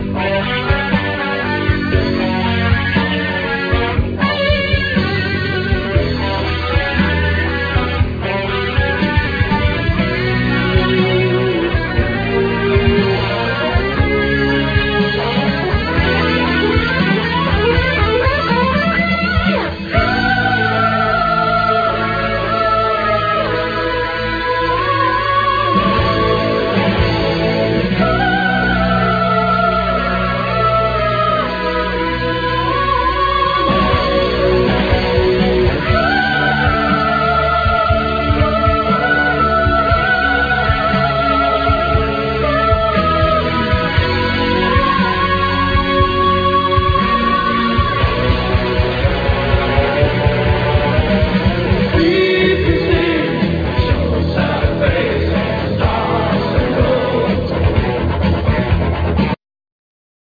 Guiats, Synthsizers, Drum machine, Vocal
Bass
Drums
Double bass
Piano, Synthesizers
Chorus